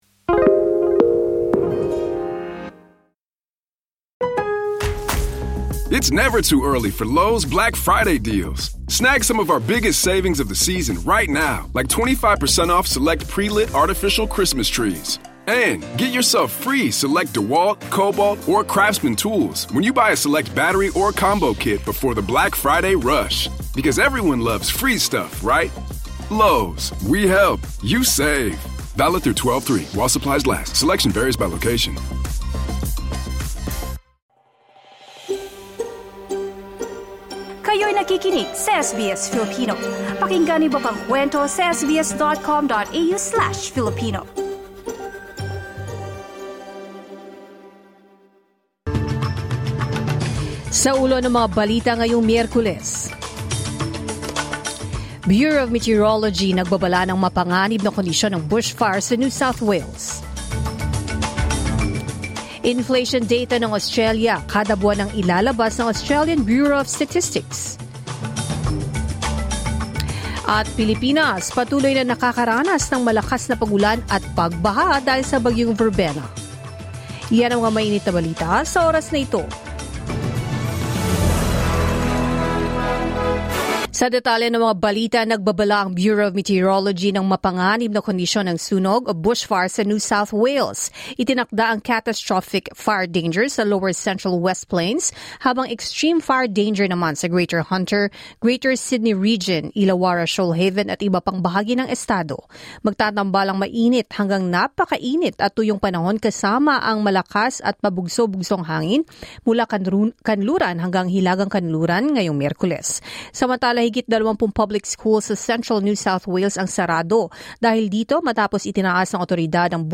Here are today's top stories on SBS Filipino.